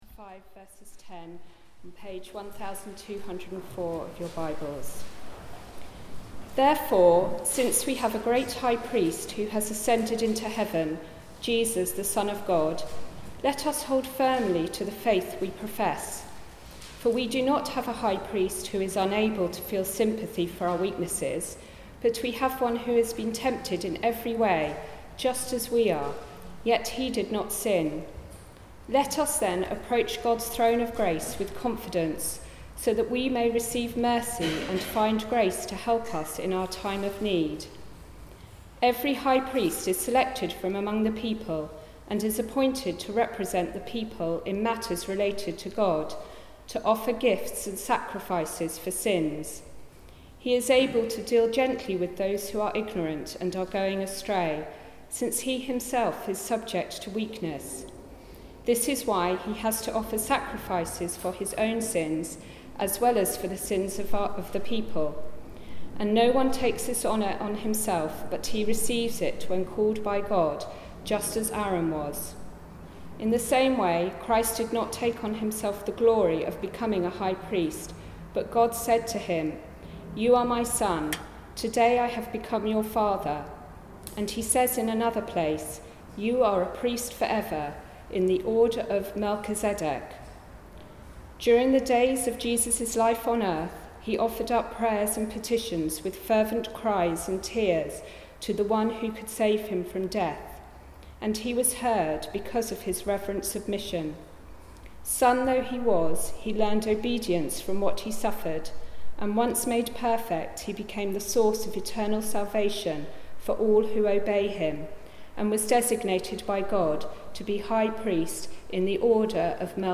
Passage: Hebrews 4:14-5:10 Service Type: Weekly Service at 4pm